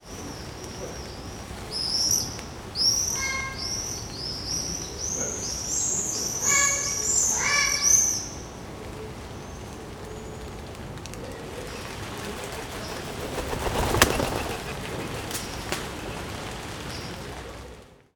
j'ai surtout trouvé d'autres oiseaux et la pluie
à la maison - SXR4+ / KM140 / KM120
pigeon.mp3